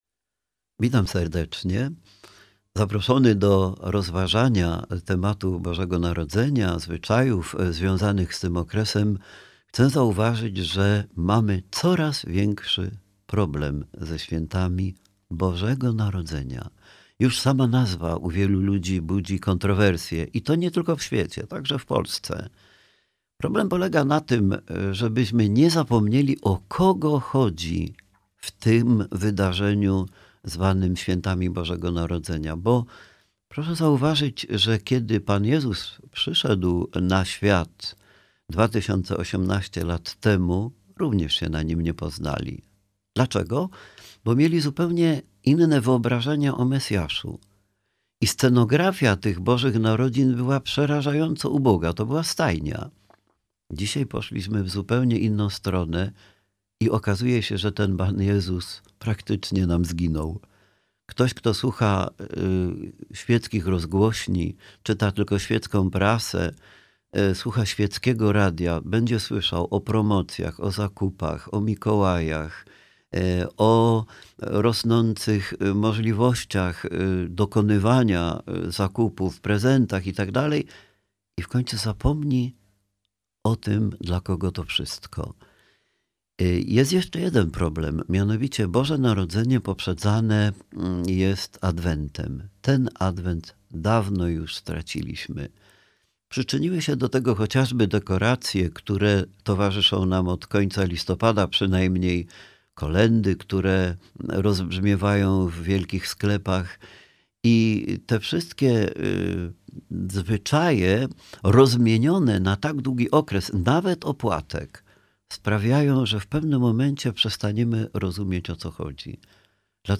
rozważania związane z istotą świąt Bożego Narodzenia. Dzieli się przemyśleniami w kontrze do pędzącej, powierzchownej i często świeckiej kultury konsumpcjonizmu.